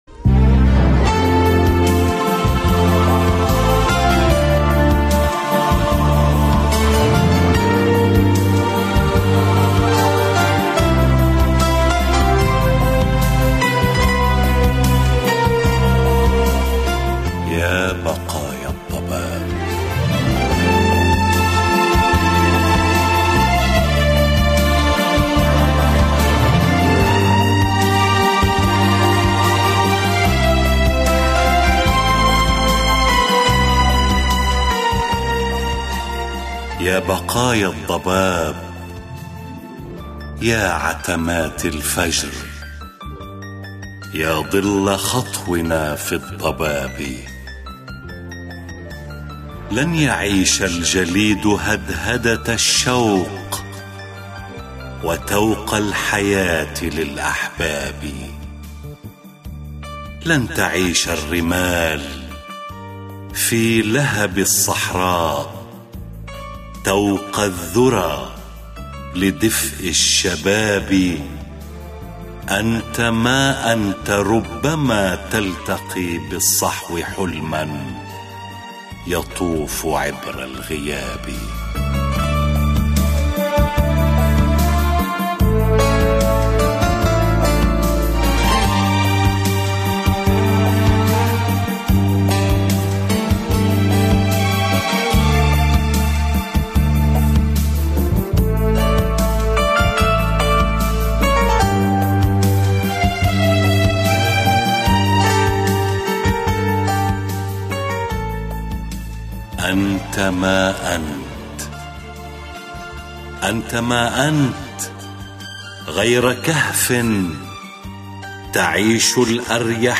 شعر